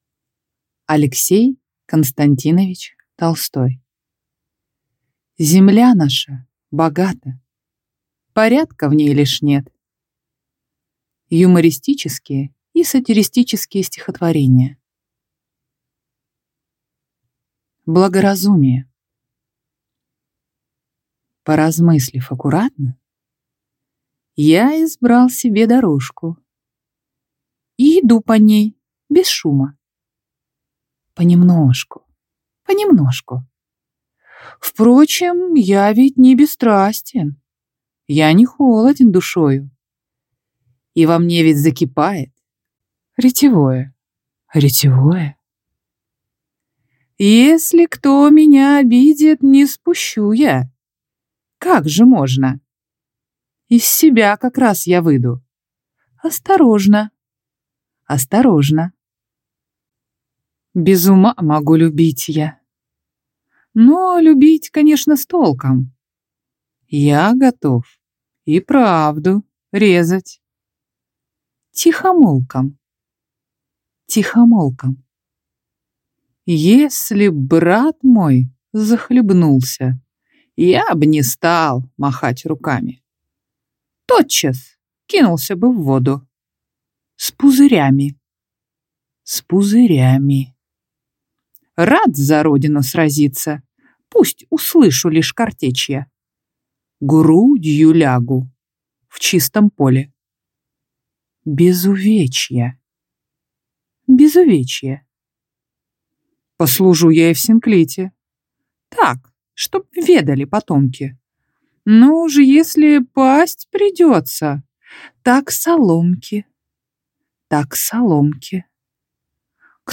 Аудиокнига Земля наша богата, порядка в ней лишь нет… (сборник)_ | Библиотека аудиокниг